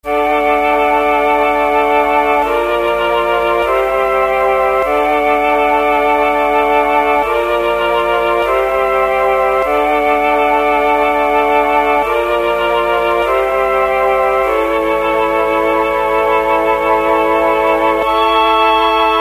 斩钉截铁的陷阱长笛
描述：用Omnisphere（莲花笛）制作的
Tag: 153 bpm Trap Loops Flute Loops 1.06 MB wav Key : Fm FL Studio